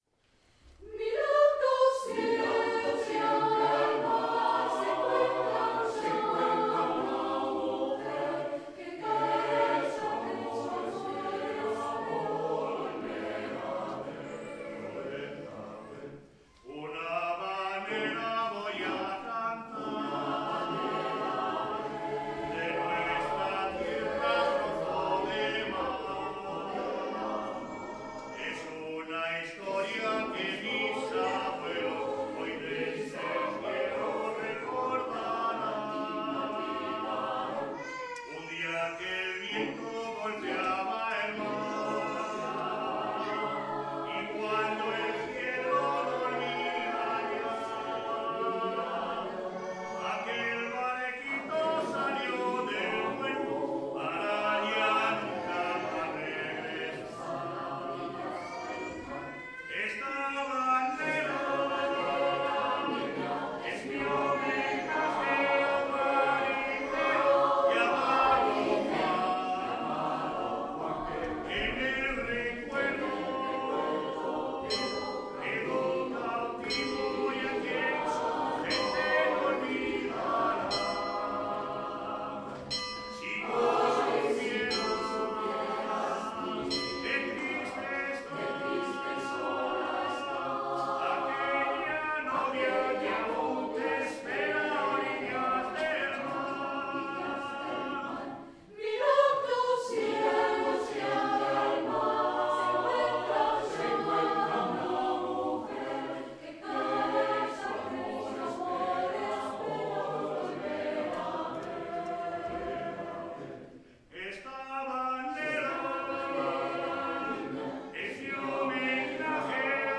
Extraits audios du concert du dimanche 12 mai 2013
Chorale HARMONIA de Valladolid A orillas del mar Traditionnel castillan Harmonia Valladolid a orillas del mar
17h00 : concert des trois chorales à l'Eglise Saint Bertrand du Mans :